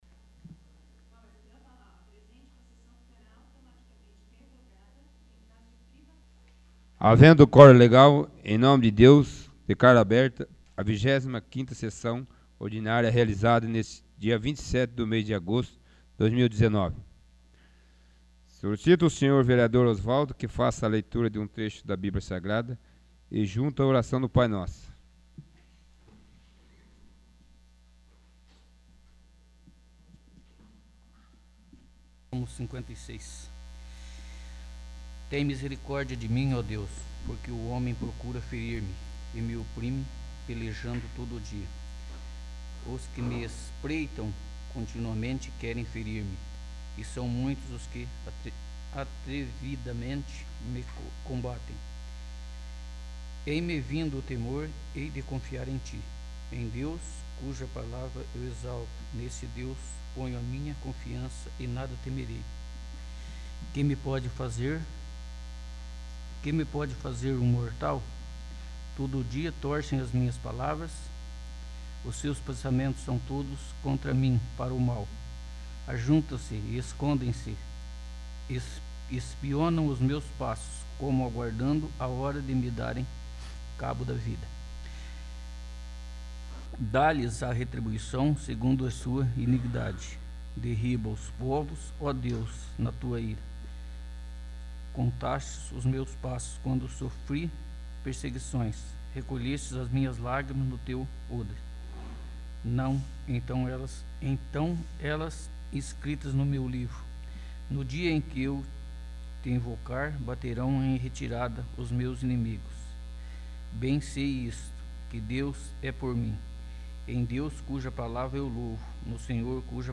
25º. Sessão Ordinária